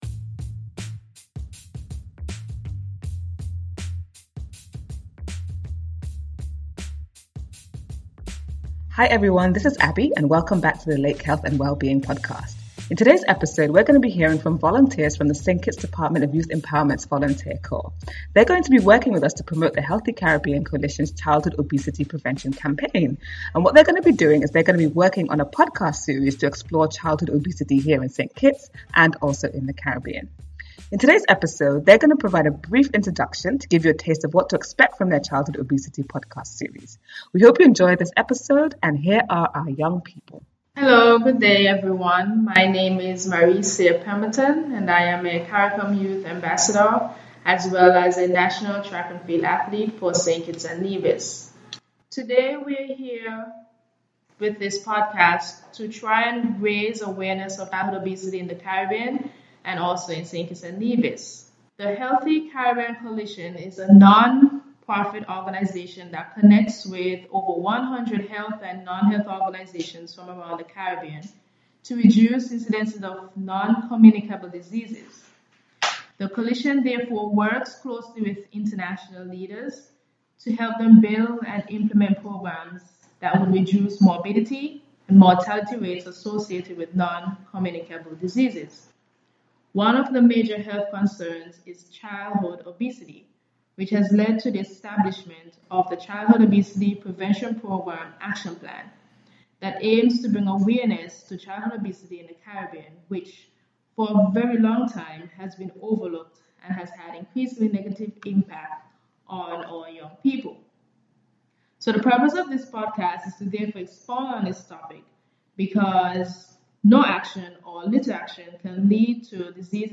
You’ll be hearing from volunteers from the Department of Youth Empowerment’s Volunteer Corp on their new podcast series on childhood obesity.